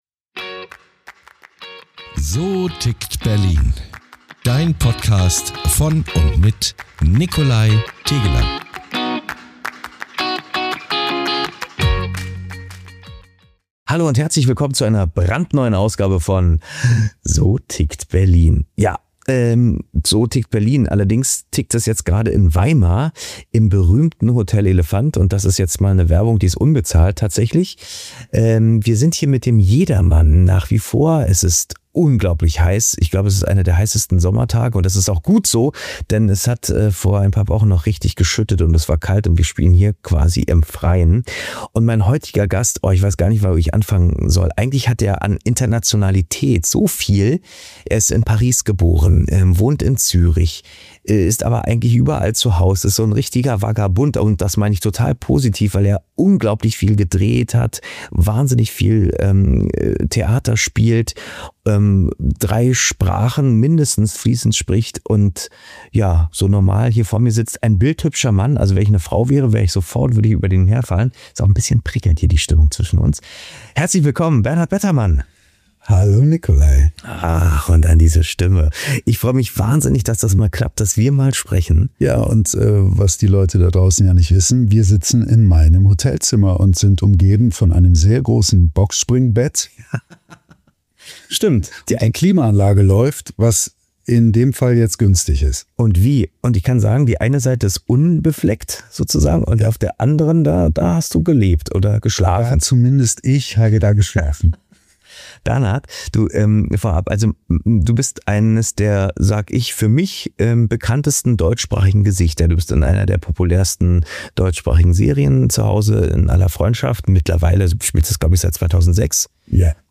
Wir sprechen über seine Anfänge auf der Bühne, über die Paraderolle als Dr. Martin Stein in „In aller Freundschaft“, über Erfolge, Zweifel und die Frage, was Wahrheit und Tiefe in der Schauspielkunst heute bedeuten. Ein Gespräch über Leidenschaft, Disziplin und die Kunst, sich immer wieder neu zu erfinden – auf der Bühne, vor der Kamera und im Leben.